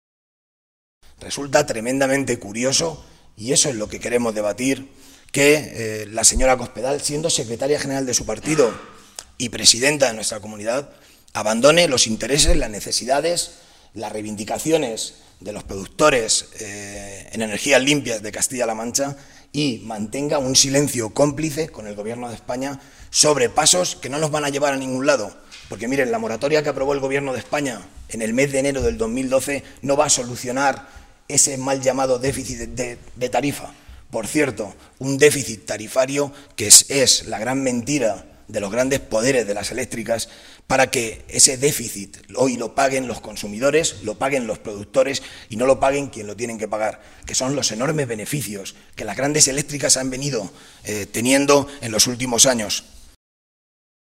Modesto Belinchón, diputado regional del PSOE de Castilla-La Mancha
Cortes de audio de la rueda de prensa